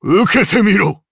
Dakaris-Vox_Attack4_jp.wav